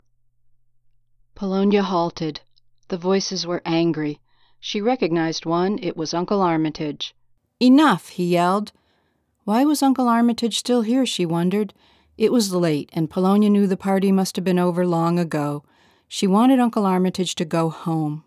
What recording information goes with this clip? I took your 75% raw clip through mastering 4 with the addition of a special filter to get rid of a power hum you picked up from somewhere. It’s your mastering up to 7 seconds and then mine from there.